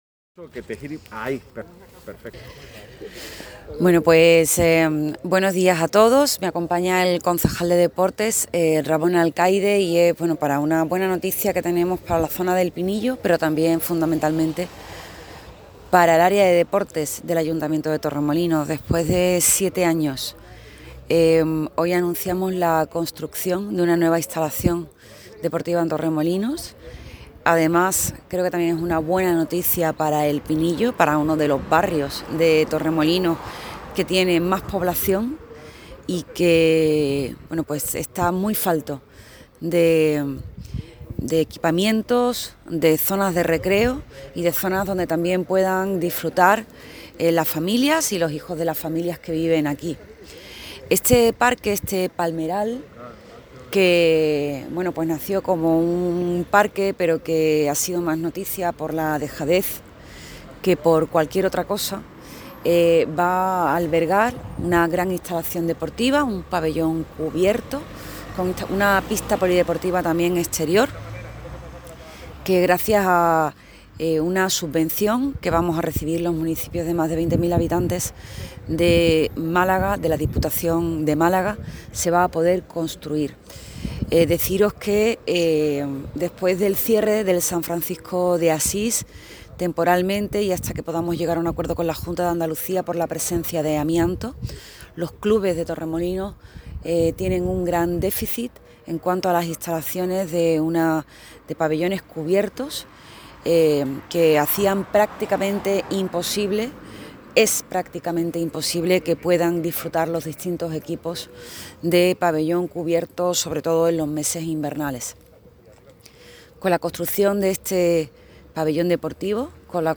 Así lo ha anunciado durante una rueda de prensa, acompañada por el concejal de Deportes, Ramón Alcaide, junto a los terrenos donde se proyecta este equipamiento, “una zona ideal que nos va a permitir llevar a cabo un equipamiento que a buen seguro será muy aprovechado por los clubes locales y por los torremolinenses a título individual”.